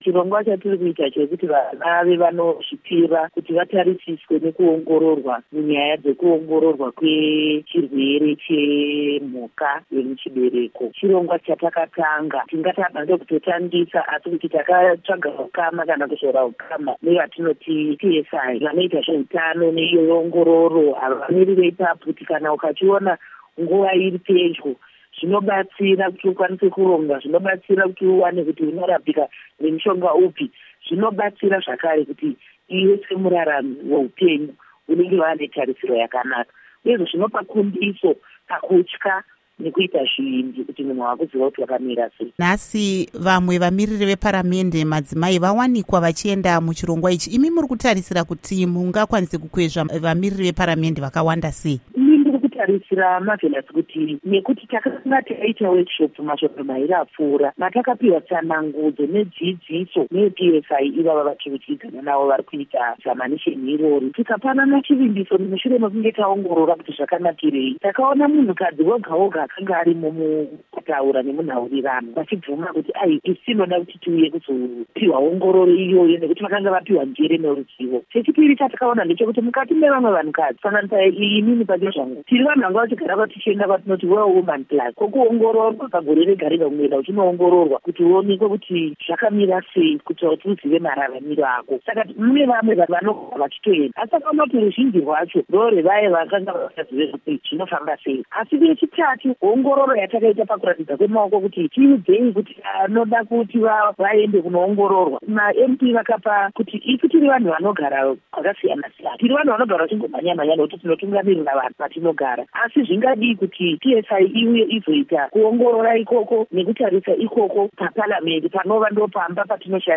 Hurukuro NaMuzvare Paurina Mpariwa